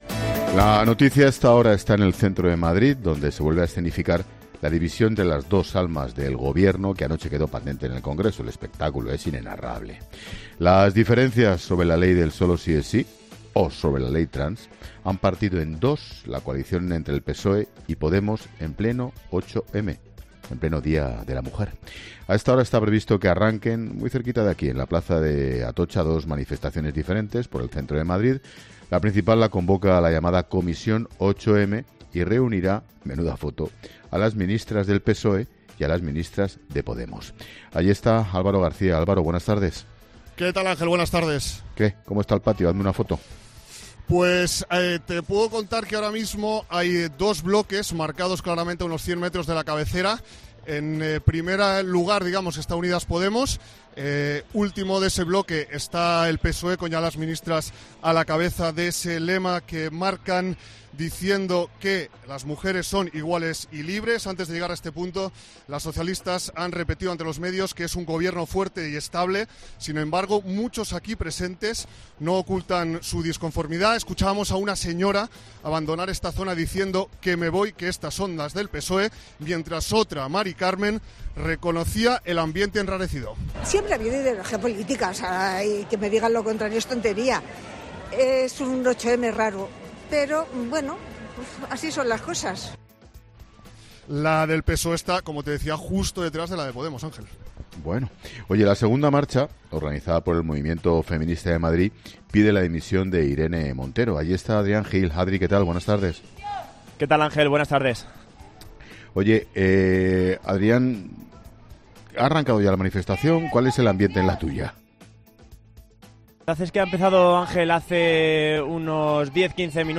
Los sonidos de las dos manifestaciones que dividen al feminismo: "Es un 8M raro, politizado"
La Linterna te cuenta los primeros sonidos en el arranque de las dos manifestaciones del 8M en Madrid